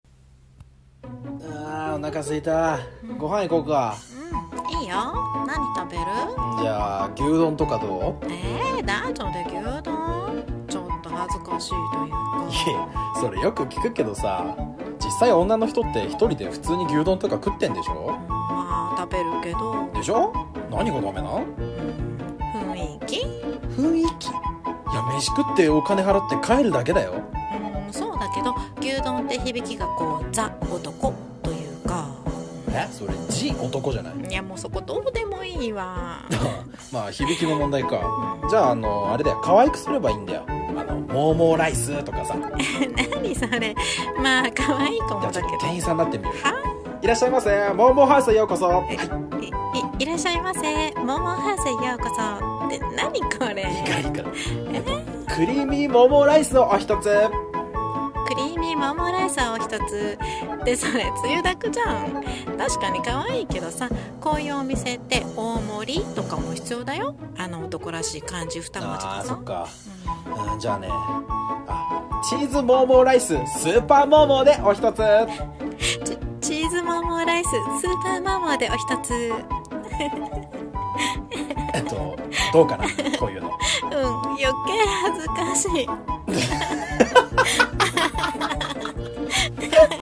オリジナル声劇